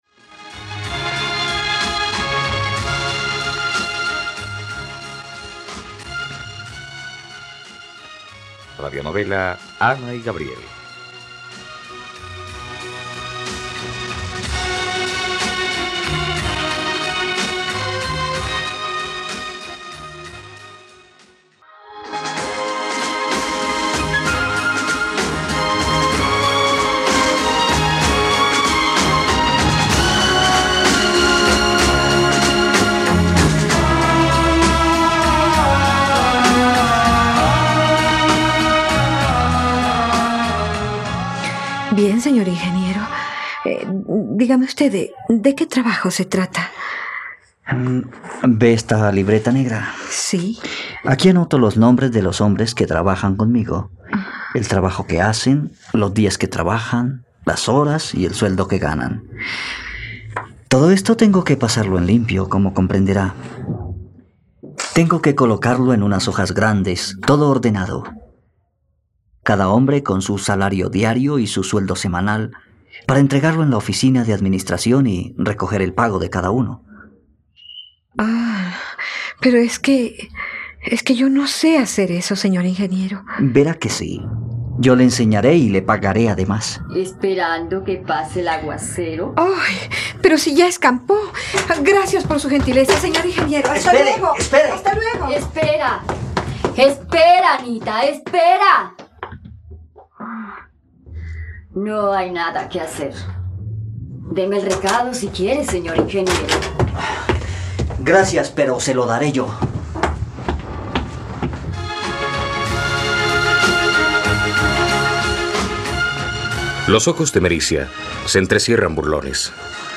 ..Radionovela. Escucha ahora el capítulo 13 de la historia de amor de Ana y Gabriel en la plataforma de streaming de los colombianos: RTVCPlay.